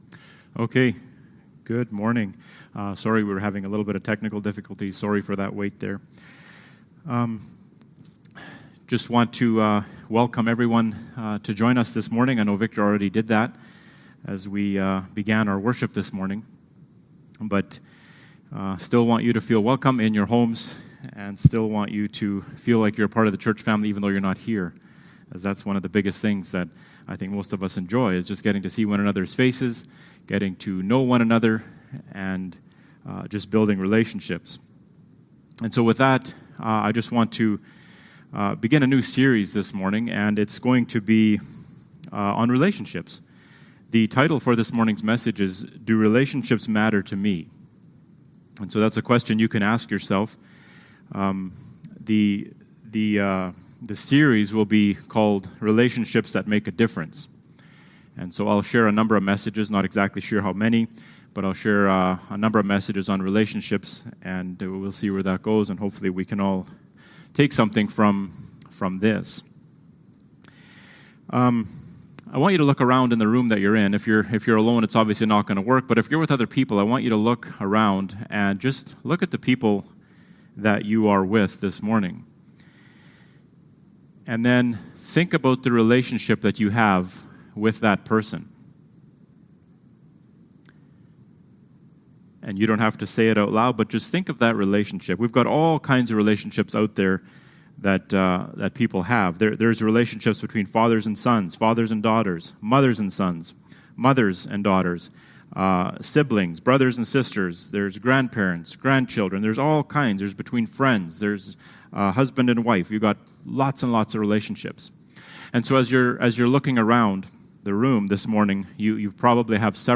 Sermons – Page 6 – Emmaus Bible Church
Service Type: Sunday Morning